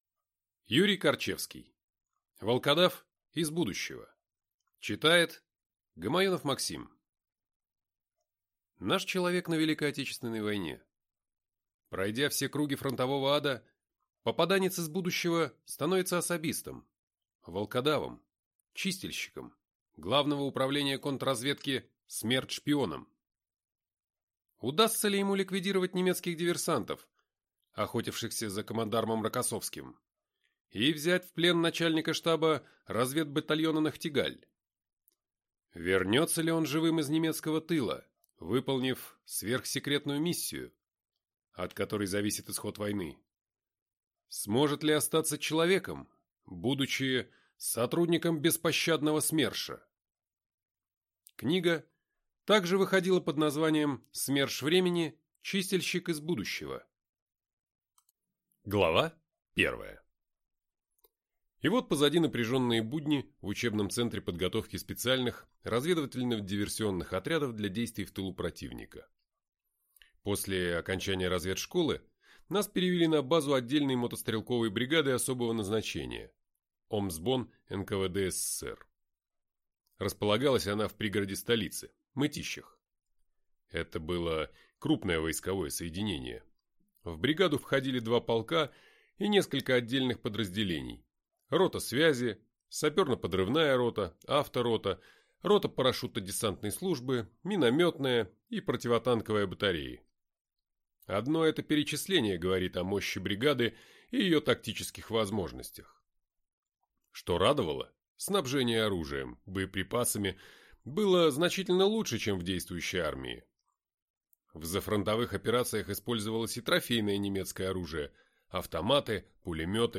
Аудиокнига «Волкодав» из будущего | Библиотека аудиокниг